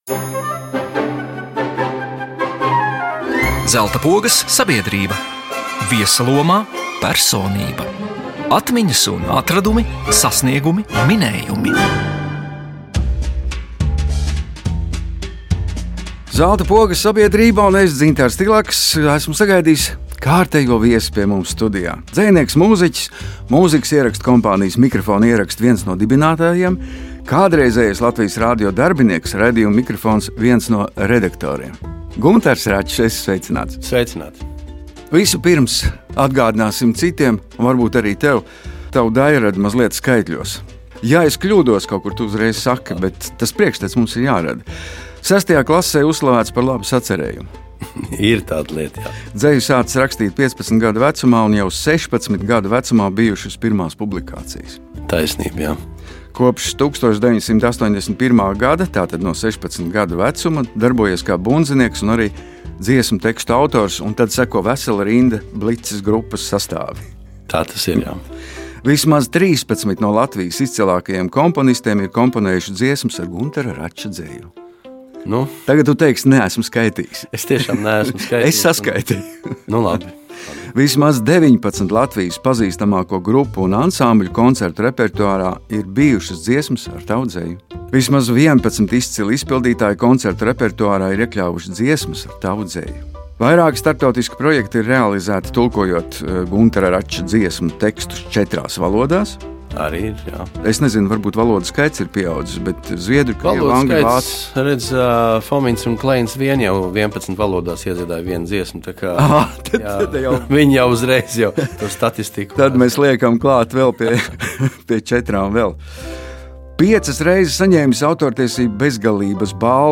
No sestajā klasē uzrakstīta laba sacerējuma līdz paša grāmatām, dzejai un dziesmām. Viesos dzejnieks, mūziķis, uzņēmējs un kādreizējais Latvijas Radio redaktors Guntars Račs.